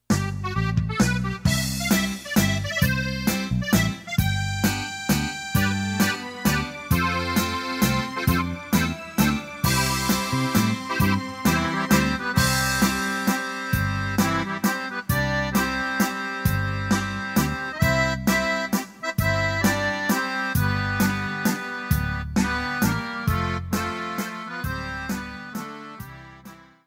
48-Norteno-2.mp3